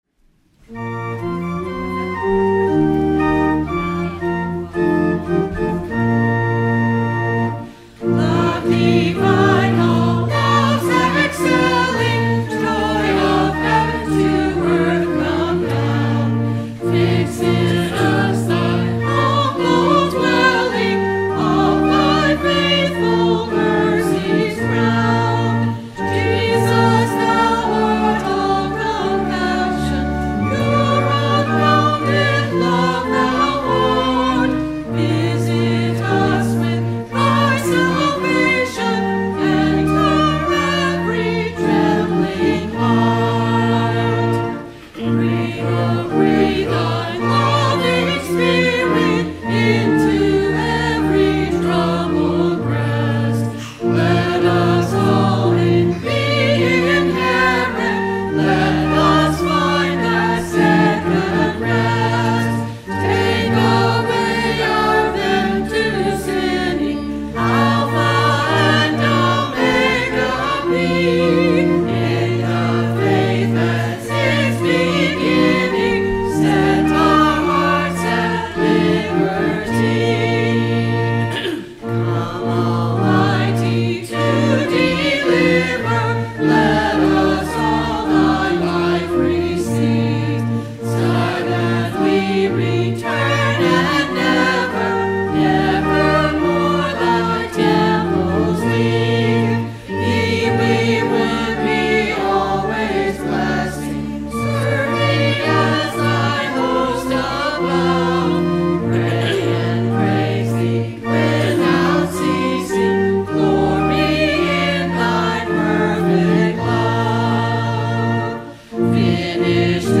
Sung by the Church and Choir.